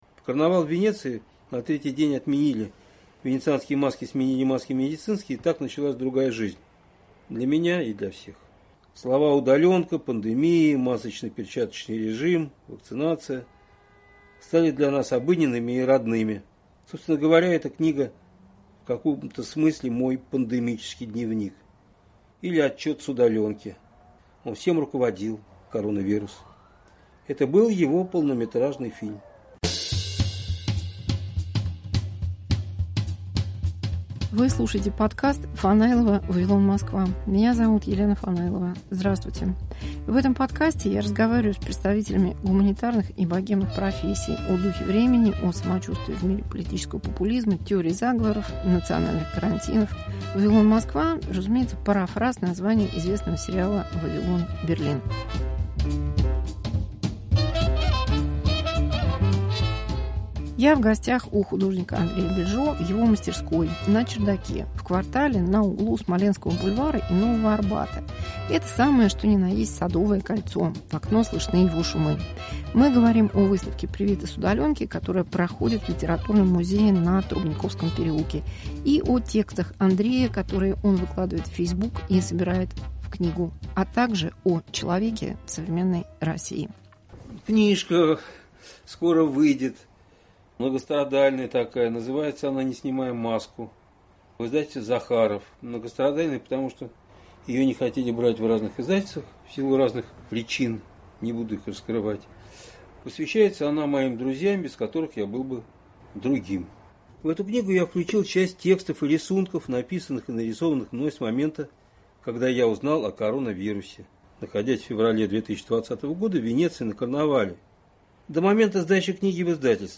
Разговор с психиатром, художником, писателем. Карантин и политика, люди и бесчеловечность